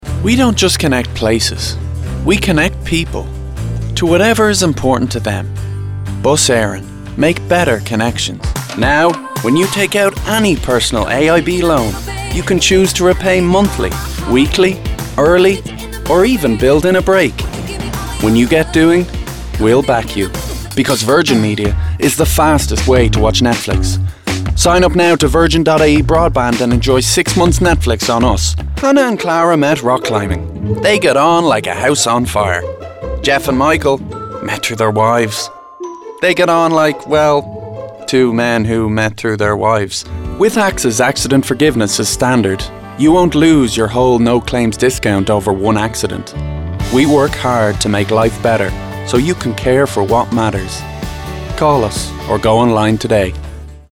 Male
20s/30s, 30s/40s
Irish Dublin Neutral, Irish Neutral